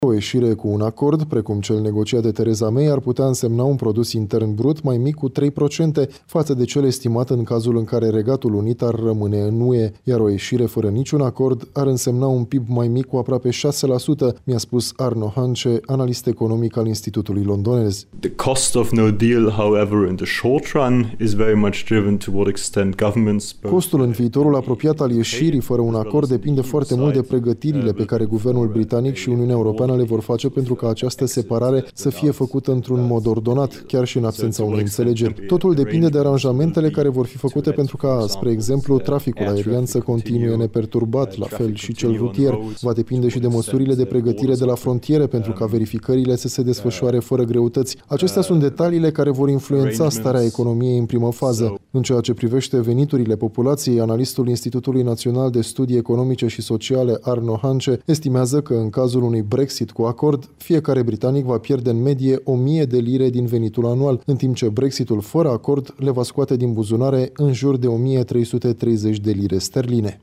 transmite de la Londra